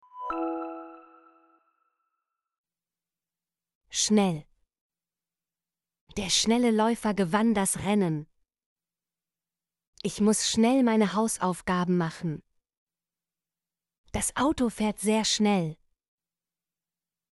schnell - Example Sentences & Pronunciation, German Frequency List